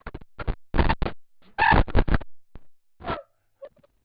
garble1.wav